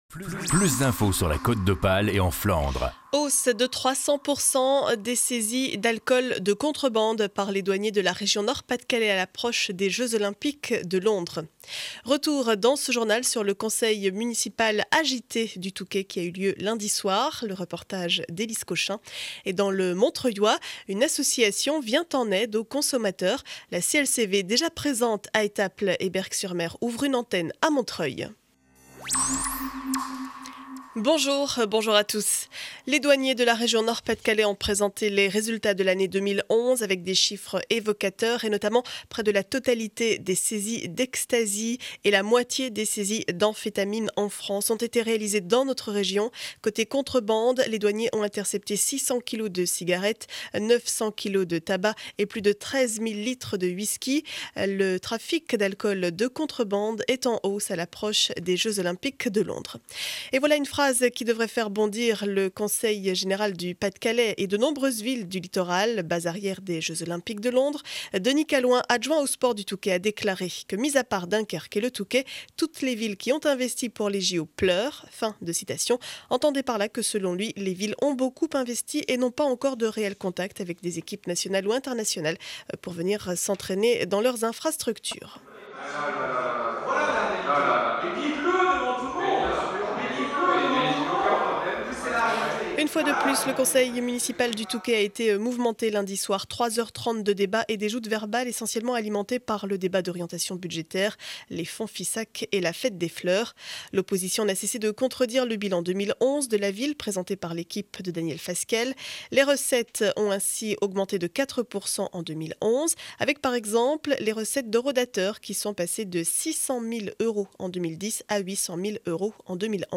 Journal du mercredi 22 février 2012 7 heures 30 édition du Montreuillois.